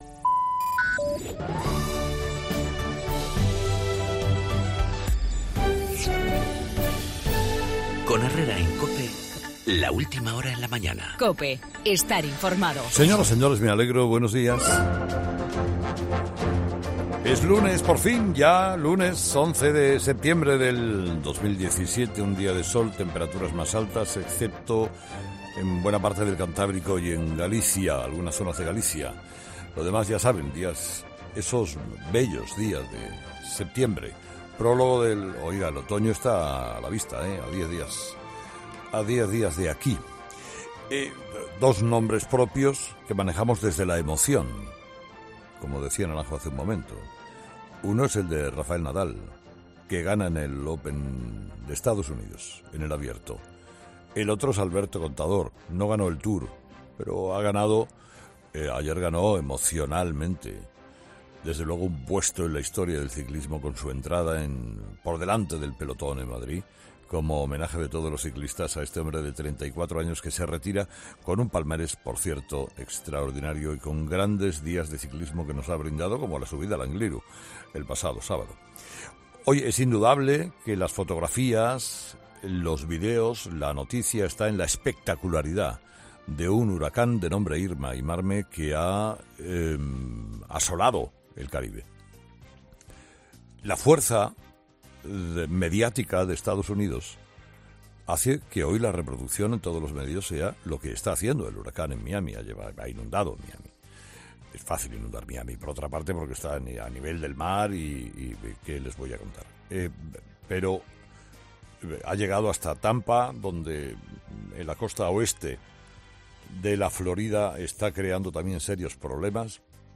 La manifestación independentista de este 11 de septiembre con motivo de la Diada de Cataluña, en el editorial de Carlos Herrera